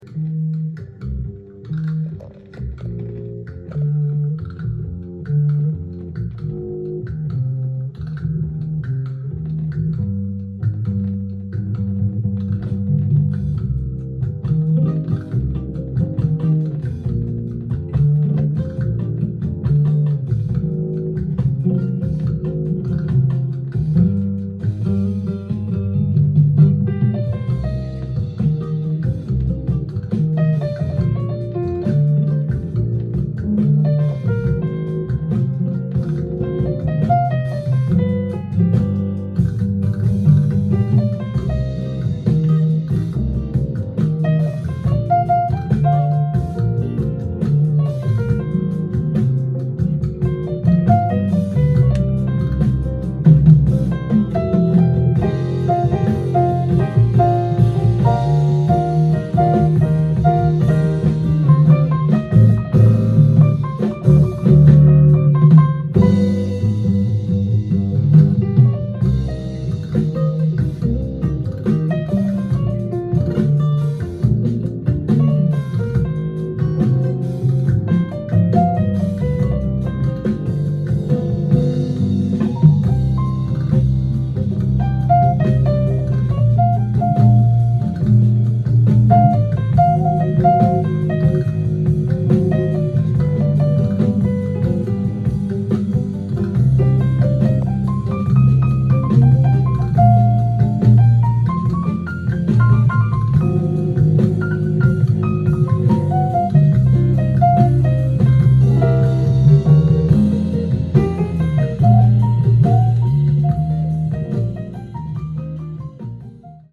ジャンル：JAZZ-ALL
店頭で録音した音源の為、多少の外部音や音質の悪さはございますが、サンプルとしてご視聴ください。
哀愁のメロディのスパニッシュ調ナンバー